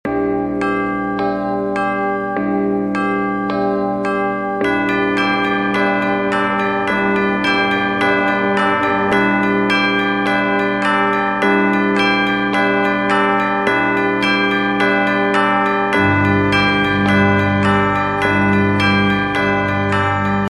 Рингтон Звон колоколов